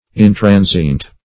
Intransient \In*tran"sient\, a.